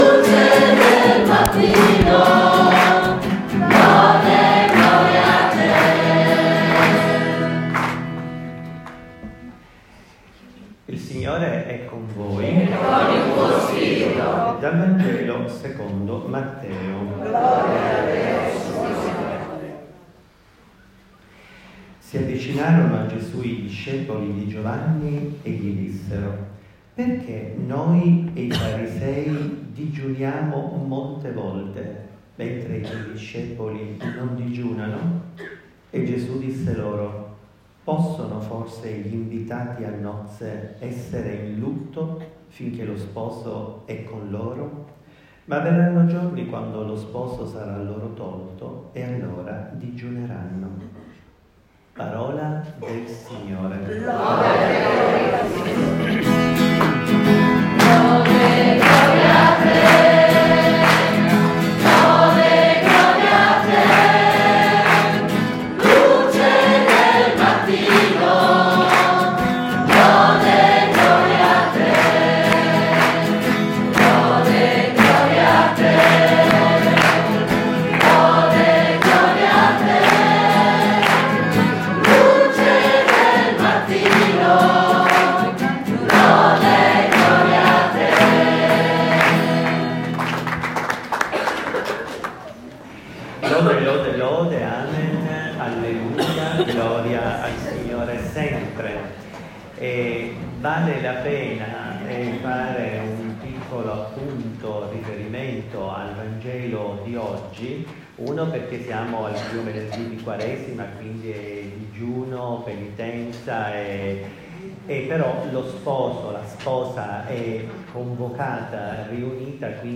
Mistero Luminoso , Rosario , annuncio , regno , amicizia , predica , gioia , comunione